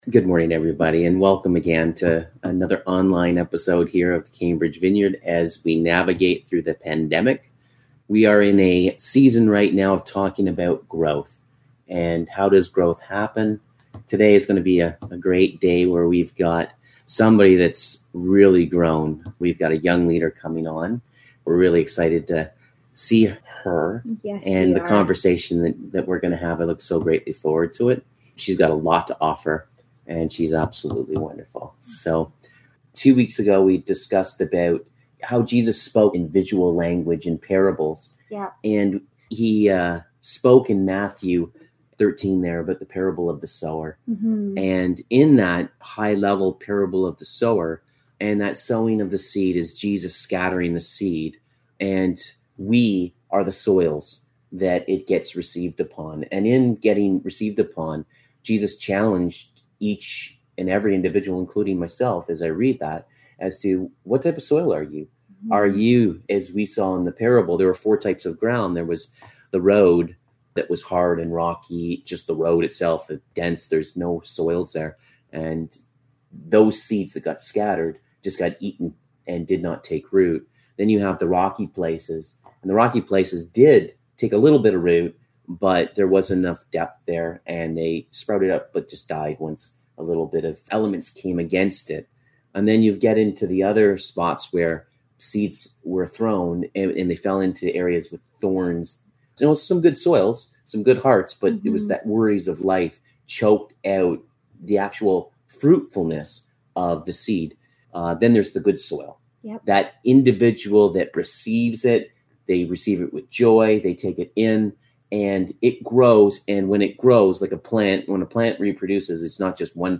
Series: Sowing Seeds of Growth Service Type: Sunday Morning « Disciples make Disciples What are you laughing at?